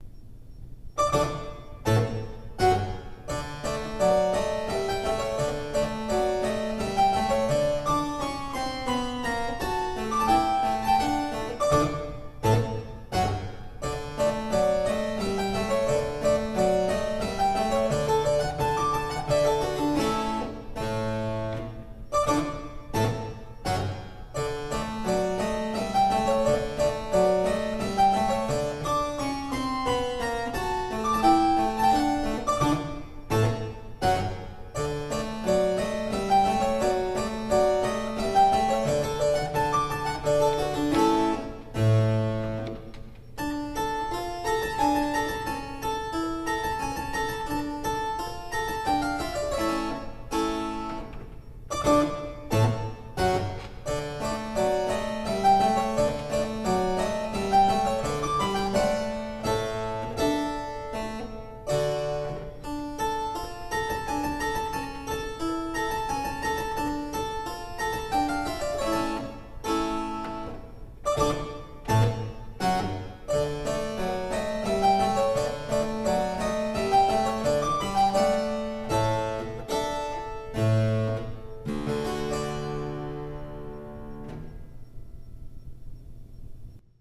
Minuet V for harpsichord and violins